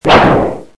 Roblox Sword Lunge Sound Effect Free Download
Roblox Sword Lunge